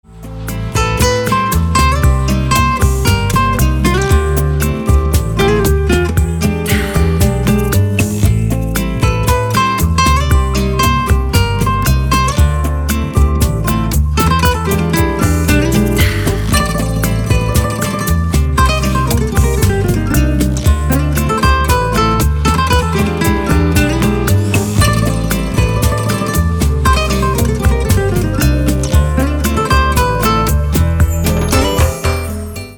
• Качество: 320, Stereo
гитара
женский голос
спокойные
без слов
Downtempo
испанская гитара